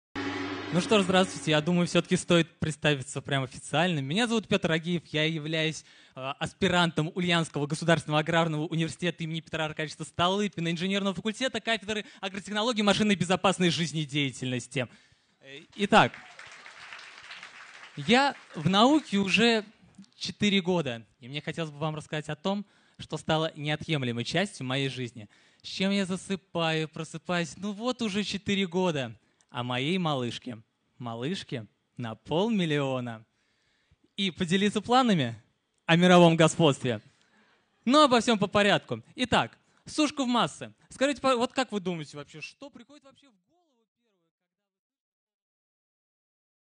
Аудиокнига Малышка на 1/2 миллиона. Сушку в массы!